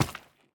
sounds / step / coral3.ogg
coral3.ogg